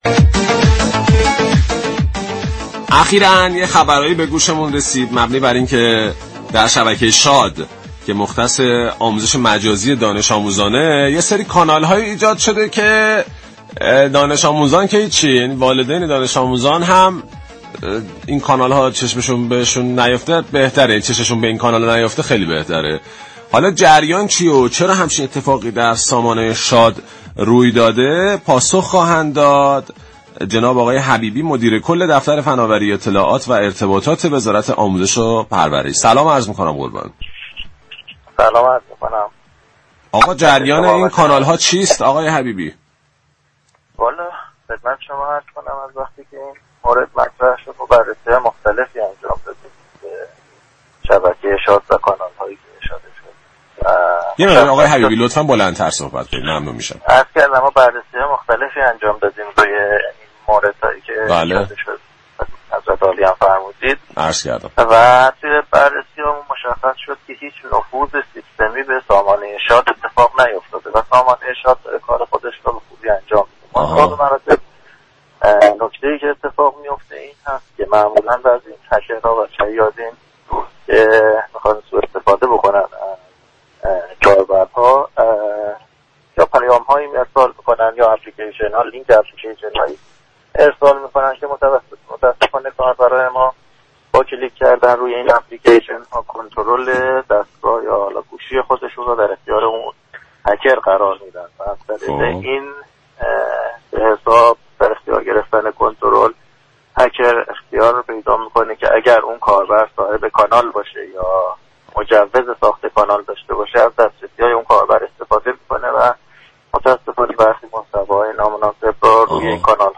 به گزارش پایگاه اطلاع رسانی رادیو تهران، محمود حبیبی مدیركل دفتر فناوری اطلاعات و ارتباطات وزارت آموزش‌وپرورش در گفتگو با پارك شهر رادیو تهران در خصوص ایجاد كانال‌های نامتعارف در شبكه شاد گفت: در بررسی‌های انجام شده متوجه شدیم هیچ نفوذ سیستمی به این سامانه اتفاق نیفتاده است.